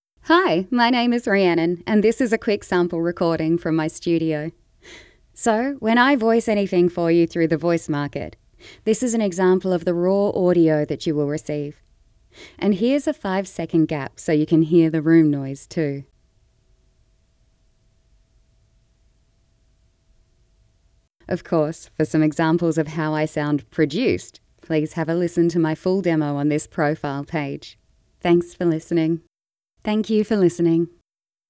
and has been described as well spoken with a clear voice that cuts through.
• Studio Sound Check
• Upbeat
• Young & Mature